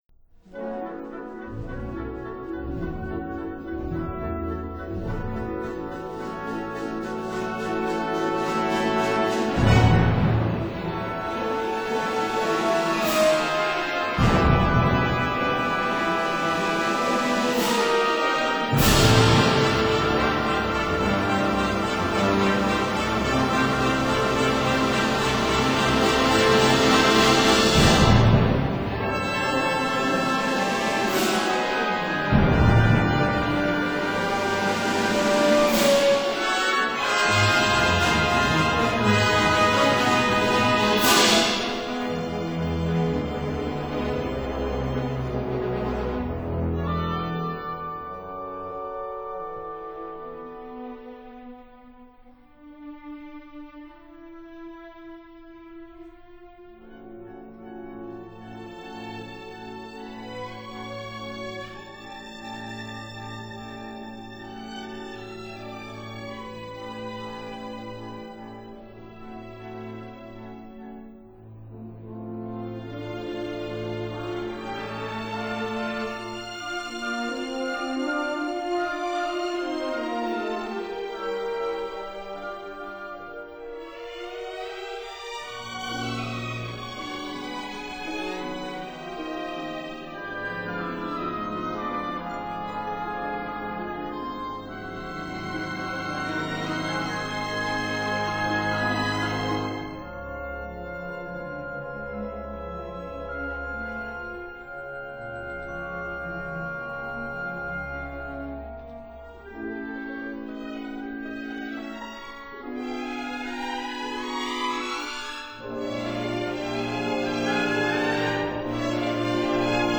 ballet